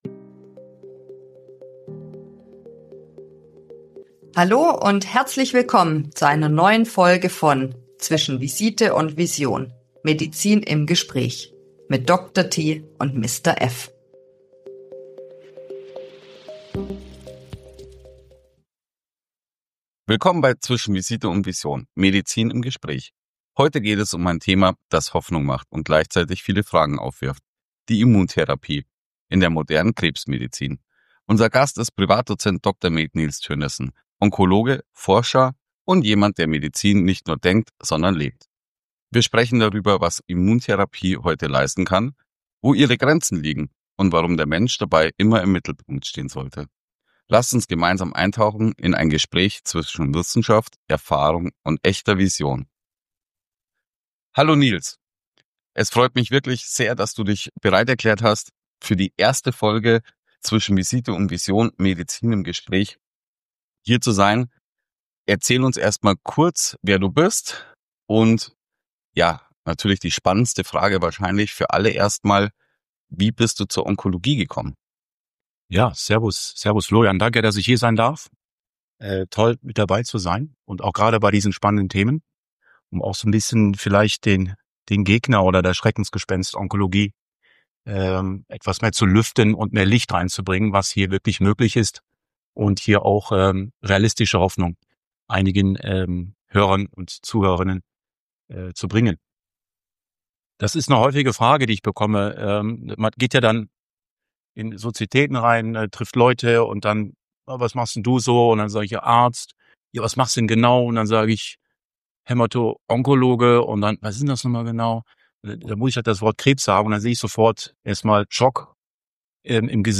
Gemeinsam tauchen wir ein in ein medizinisches Gespräch zwischen Forschung, Erfahrung – und echter Vision.